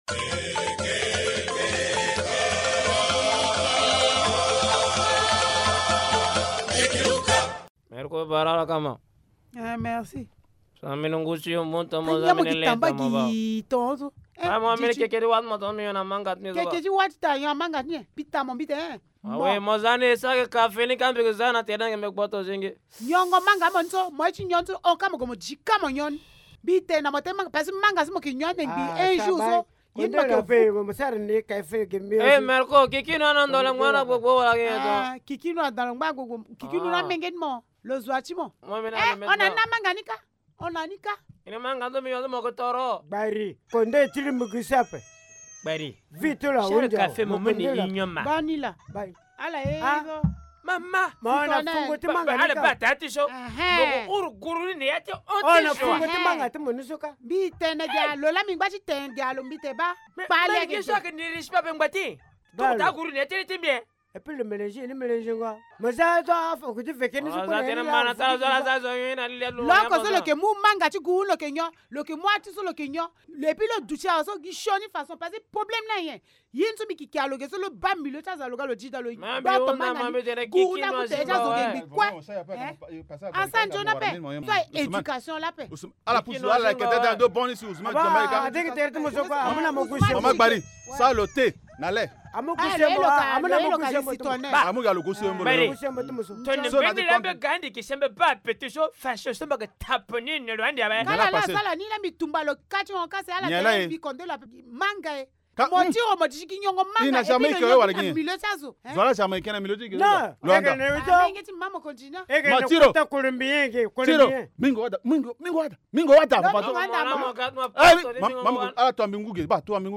Linga théâtre : la consommation de cigarettes en public irrite les sages du village